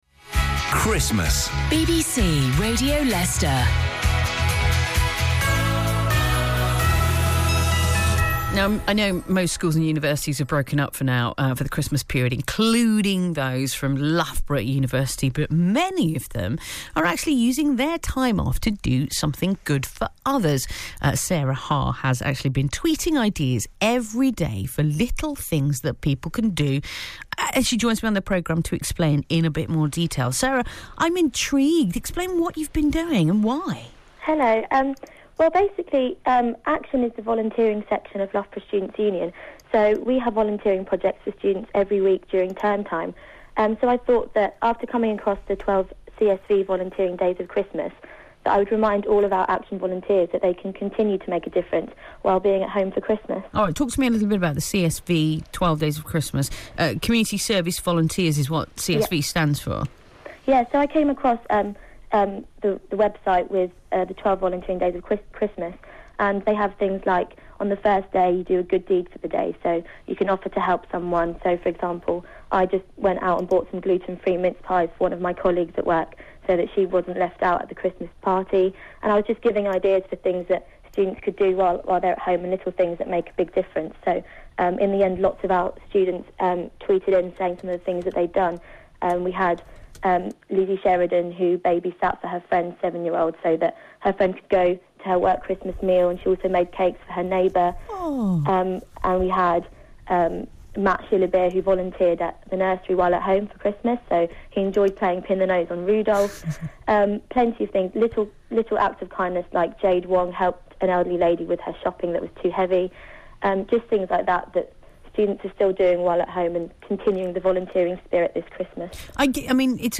Broadcast on BBC Radio Leiceister on 23.12.13